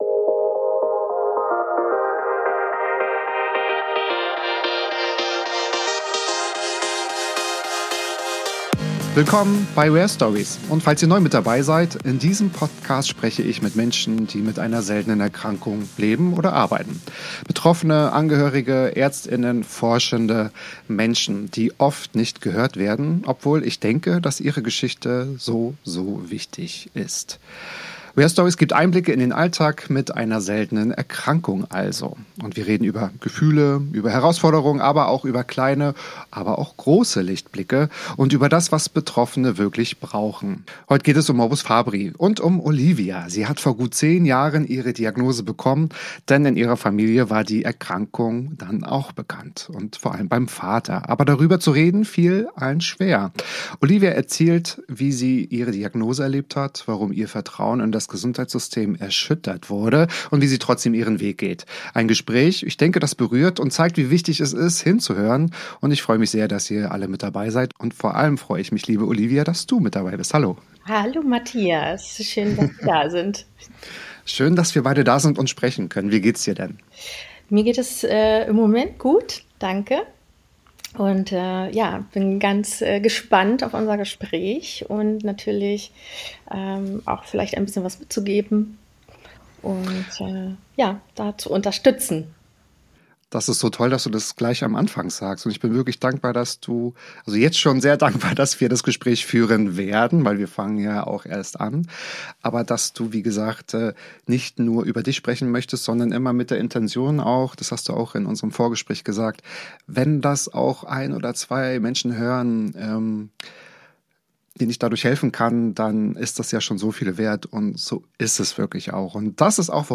Ein Gespräch über das Ringen um Sichtbarkeit mit einer seltenen Erkrankung: sensibel, ehrlich und stark.